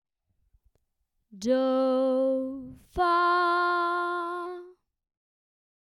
Major key with tonic and subdominant emphasized
Hearing-Harmonies-1-Ex-6-tonic-subdominant.mp3